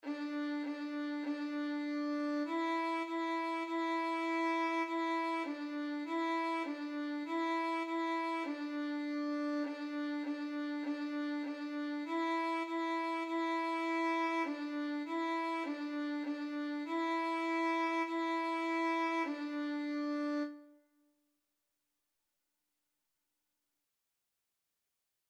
2/4 (View more 2/4 Music)
D5-E5
Violin  (View more Beginners Violin Music)
Classical (View more Classical Violin Music)